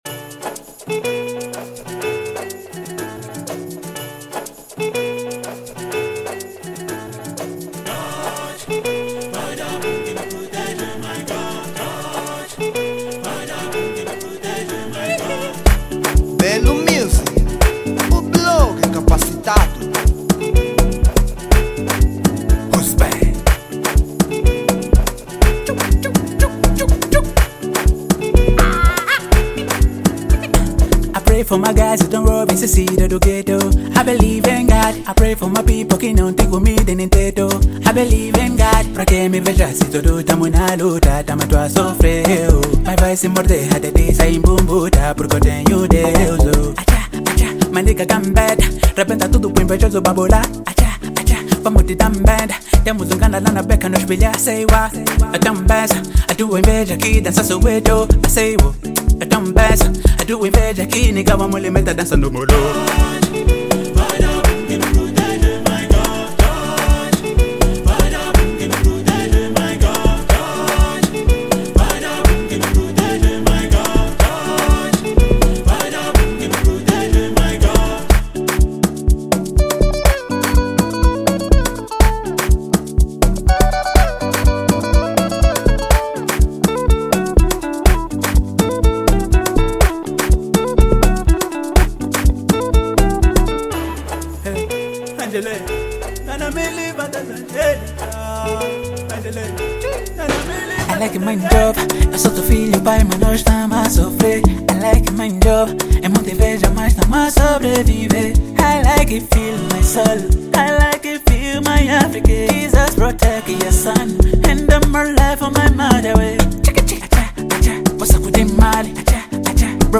Género : Afro Vibe